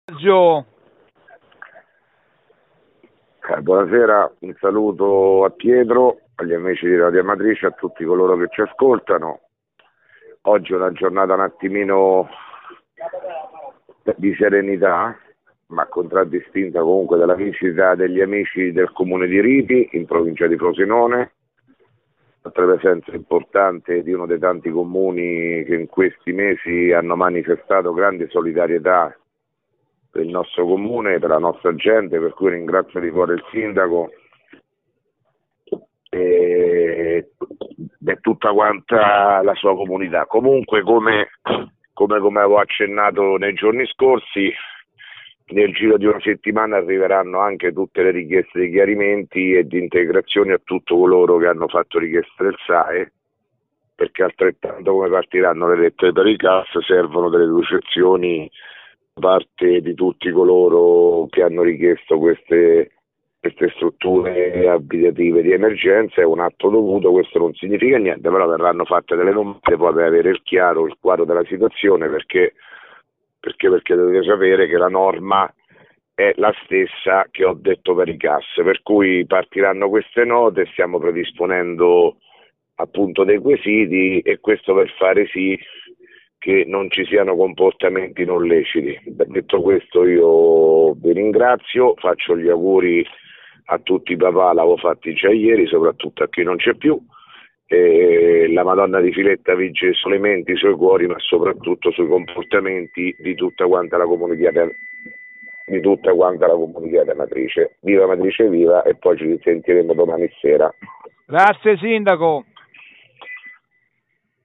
RADIO AMATRICE: MESSAGGIO DEL SINDACO PIROZZI (19 MAR 2017) - Amatrice